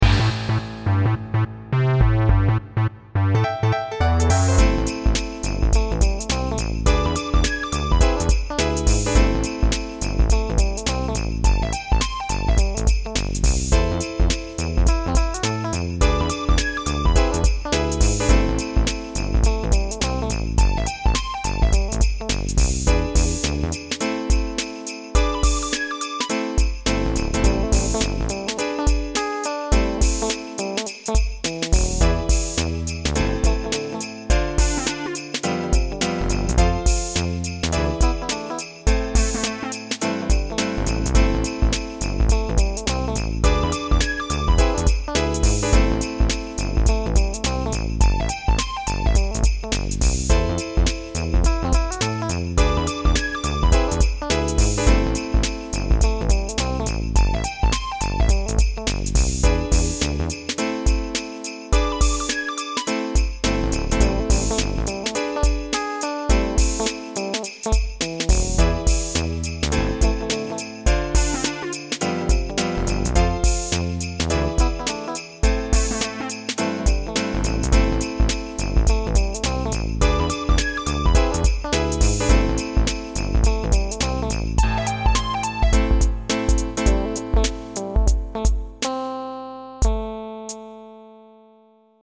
オリジナル(インスト)